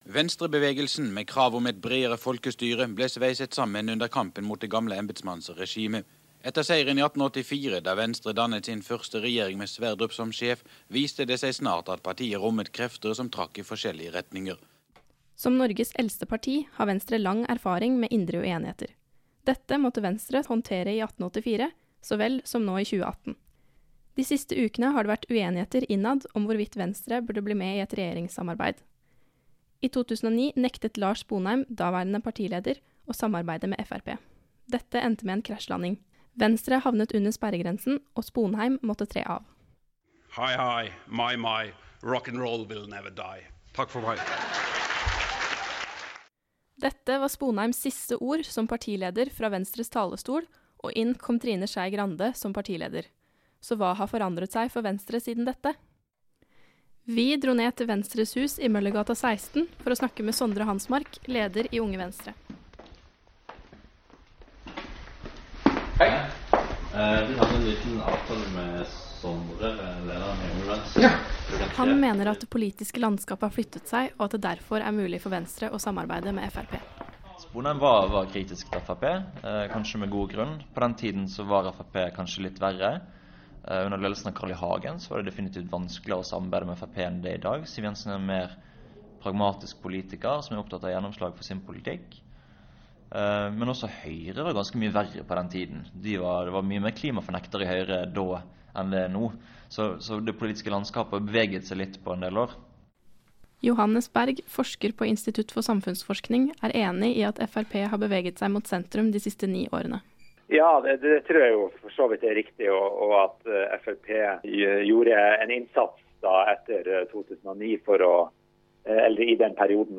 Vi har pratet med flere studenter på OsloMet og UiO.